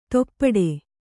♪ toppaḍe